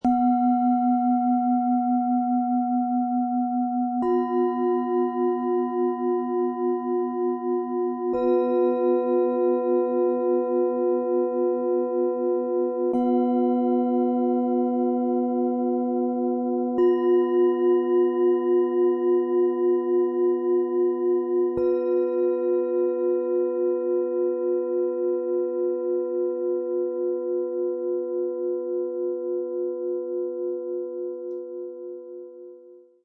Fühle Ruhe, vertraue deinem Bauch und lebe deine innere Stärke - Set aus 3 Planetenschalen, Ø 11,8 -15 cm, 1,27 kg
Der Gesamtklang wirkt ruhig, warm und klärend.
Sanfter, heller Ton für ruhiges Lauschen und kleine Klangreisen.
So entsteht ein klarer, tragender Klang.
Im Sound-Player - Jetzt reinhören hören Sie den Originalton genau dieser drei Schalen. Lauschen Sie, wie die ruhigen, klaren Schwingungen wirken und feine Balance schenken.
Tiefster Ton: Mond – Geborgenheit & Intuition
Mittlerer Ton: Wasser – Fließen & Zentrierung
Höchster Ton: Lilith – Präsenz & Selbstbestimmung
MaterialBronze